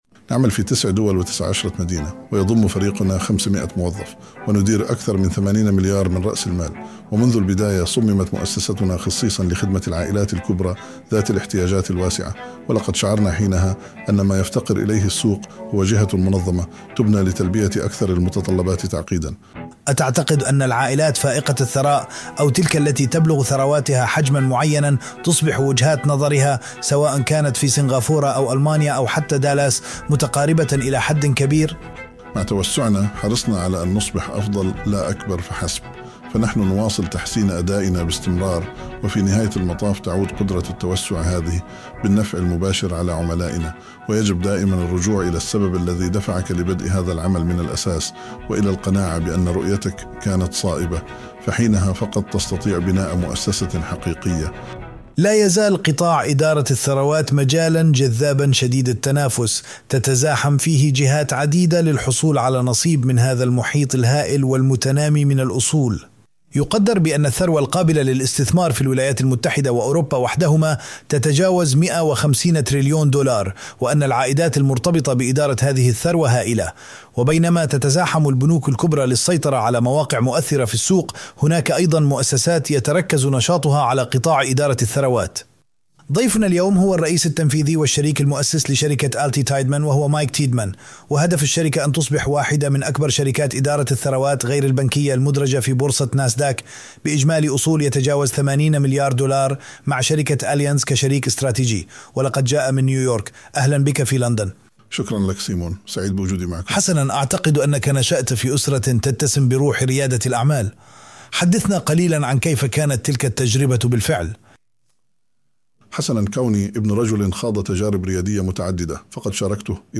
في هذه المحادثة